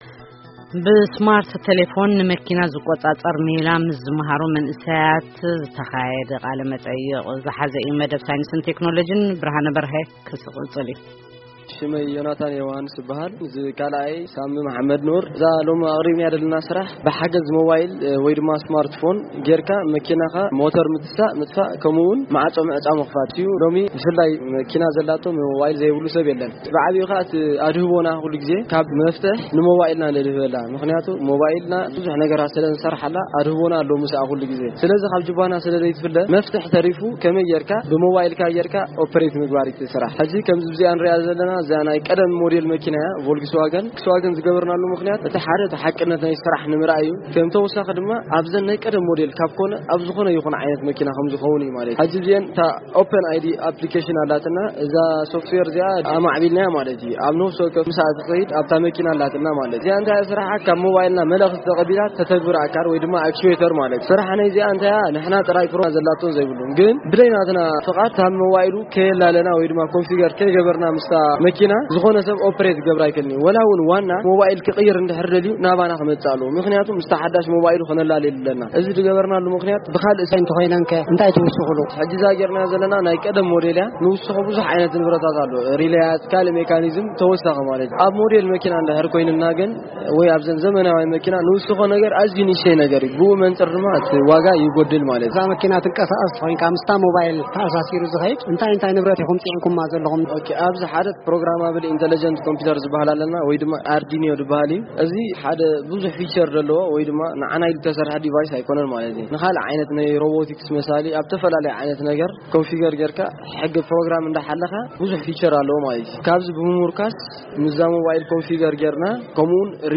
ብሞባይል ቴሌፎን ወይ ስማርት ፎን ንመኪና ናይ ምቁጽጻር ሜላ ዝመሃዙ ተማሃሮ በዚ ዝተኣታተውዎ ምሕዞ ብሞባይል ቴሌፎን ሞተረ መኪና፡ ማዕጾ ምክፋትን ምዕጻውን ዝአመሰሉ ተግባራት ንምክያድ ከምዝከአል ይገልጹ። ምስቶም መንእሰያት ዝተኻየደ ቃለ መጠይቕ ኣብዚ ምስማዕ ይክኣል። ብሞባይል ቴሌፎን ንመኪና ናይ ምቁጽጻር ሜላ ዝመሃዙ መንእሰያት